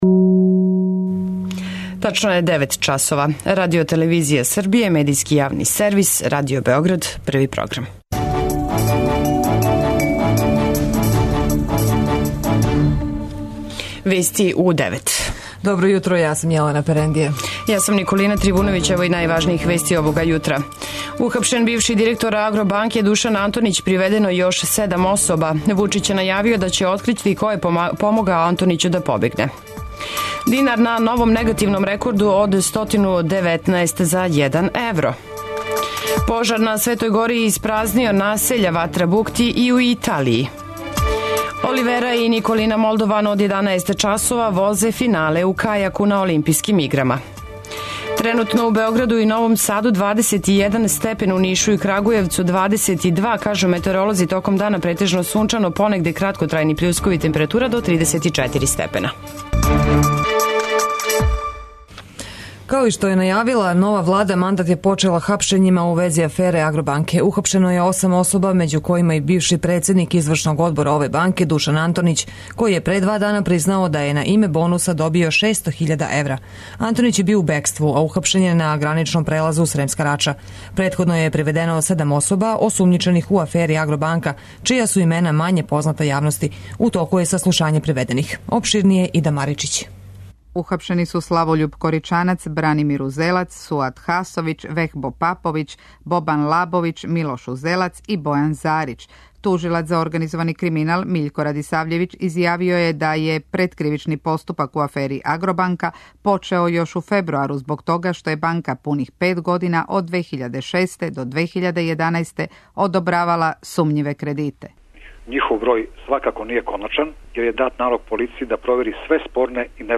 преузми : 10.36 MB Вести у 9 Autor: разни аутори Преглед најважнијиx информација из земље из света.